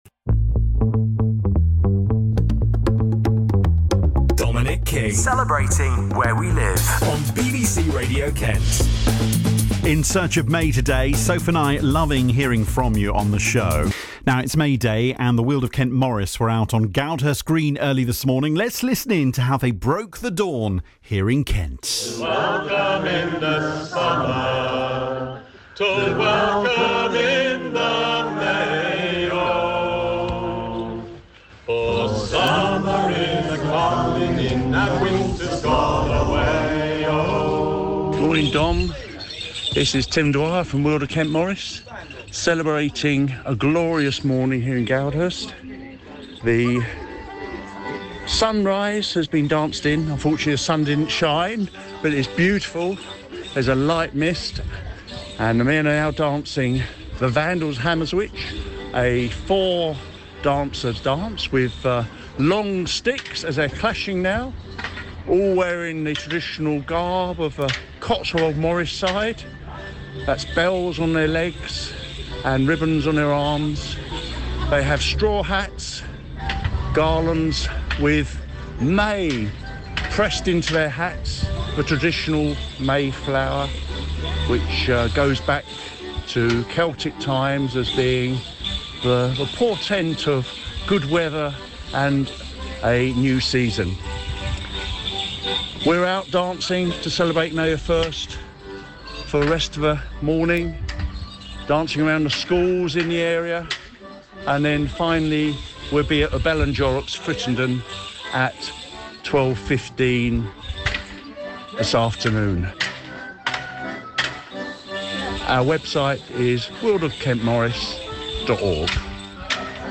Our recording as transmitted by Radio Kent on Wednesday May 1st 2024
May Day 2024 interview on Radio Kent.mp3